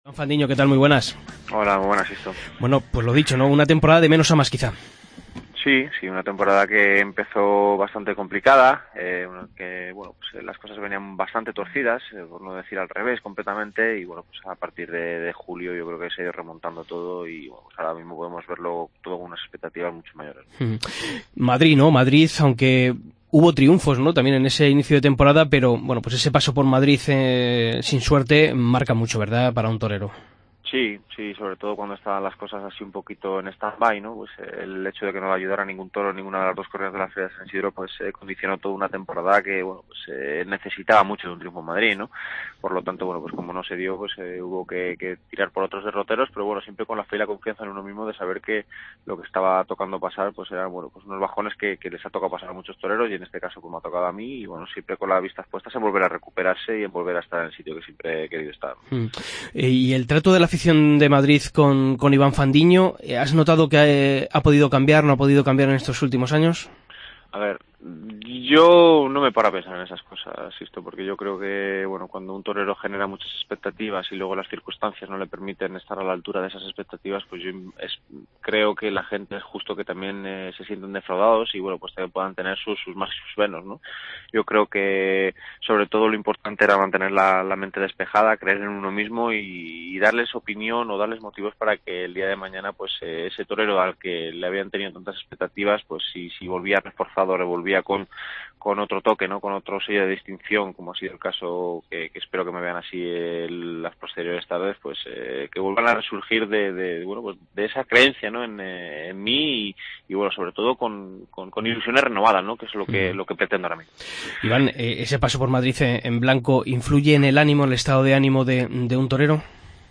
Escucha la entrevista a Iván Fandiño en El Albero